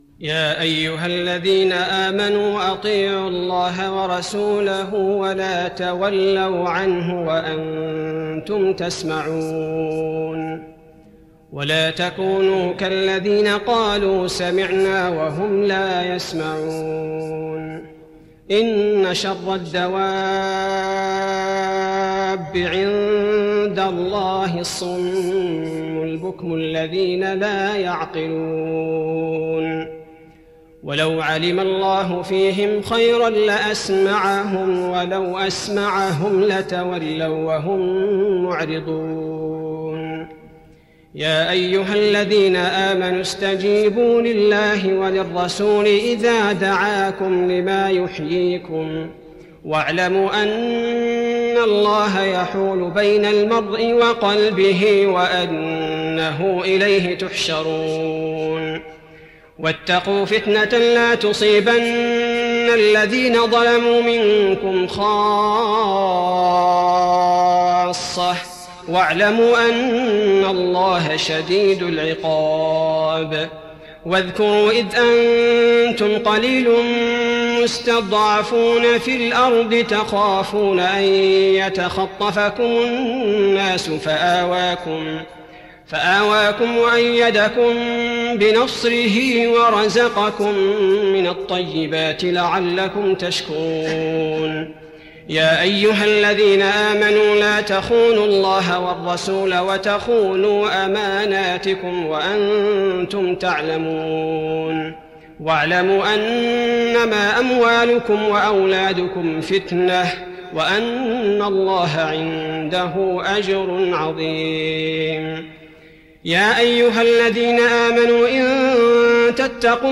تراويح رمضان 1415هـ من سورتي الأنفال (20-75) التوبة (1-27) Taraweeh Ramadan 1415H from Surah Al-Anfaal and At-Tawba > تراويح الحرم النبوي عام 1415 🕌 > التراويح - تلاوات الحرمين